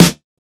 • Old School Subtle Reverb Snare Drum Sample F Key 53.wav
Royality free steel snare drum sound tuned to the F note. Loudest frequency: 2028Hz
old-school-subtle-reverb-snare-drum-sample-f-key-53-8Ws.wav